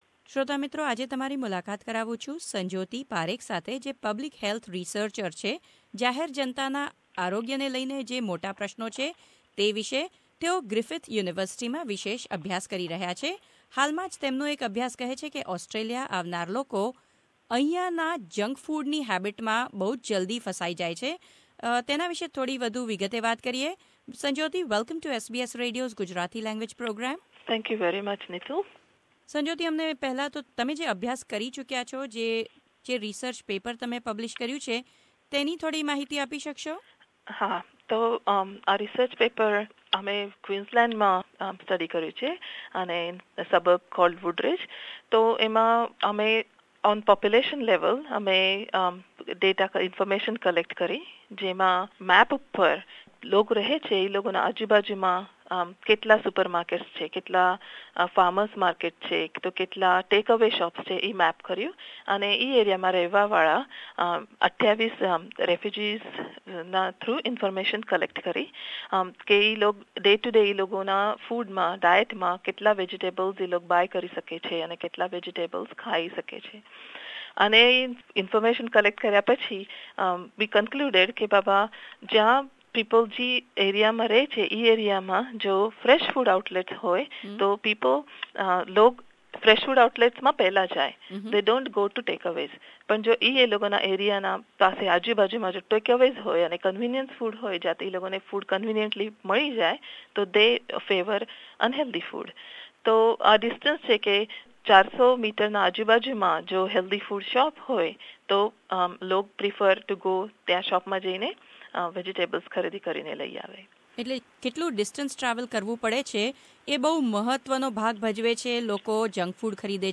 વાર્તાલાપ .